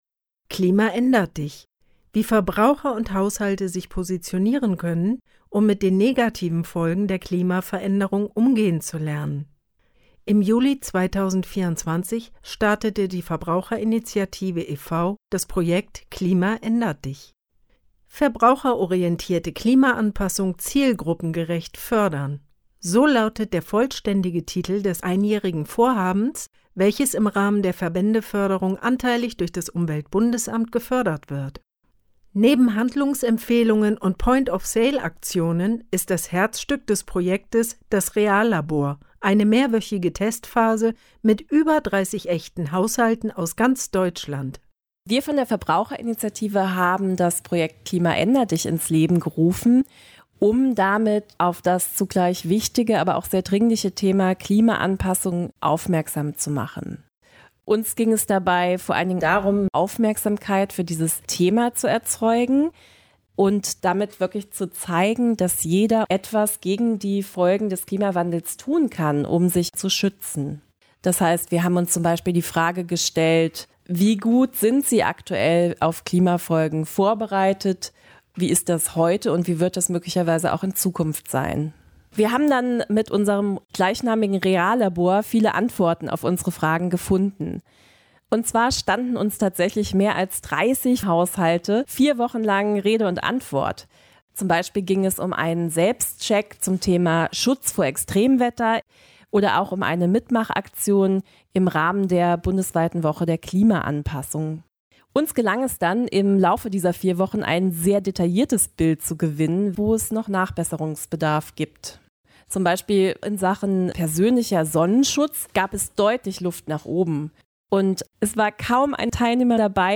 Presseservice Radiobeiträge